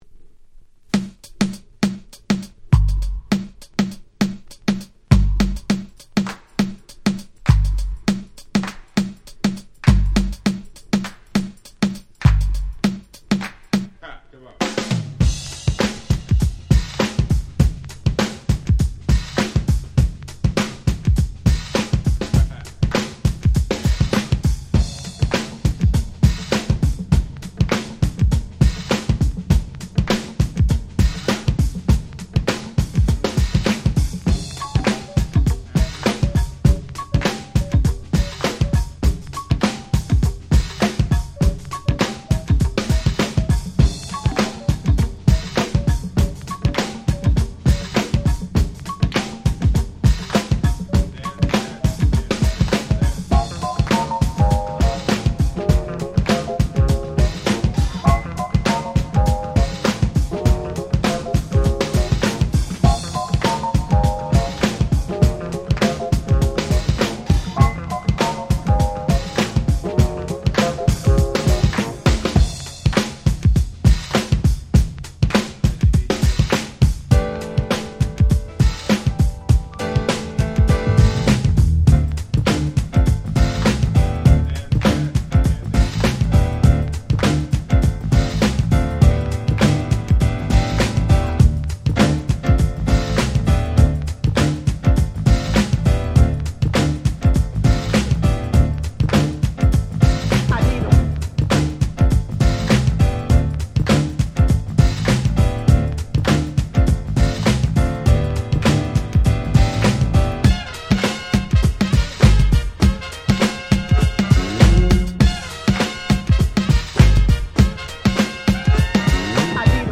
UK Original Press.
UK Soul/Ground Beat Classic !!